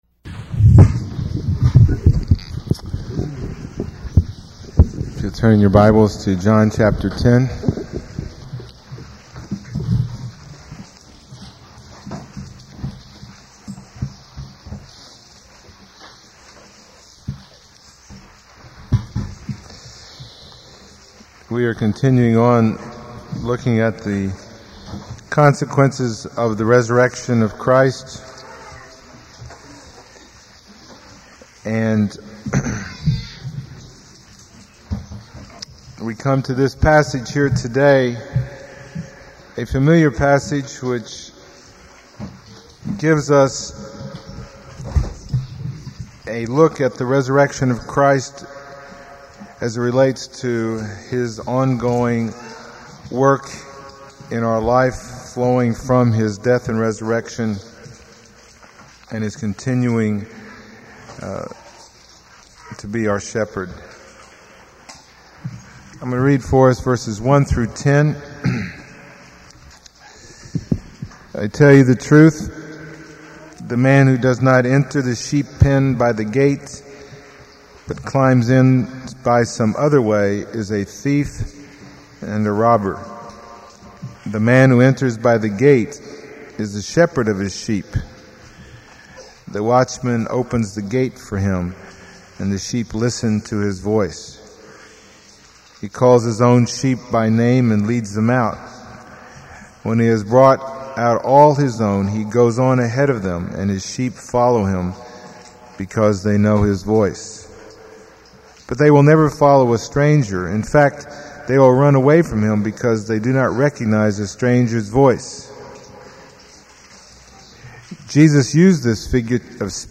Sermon 38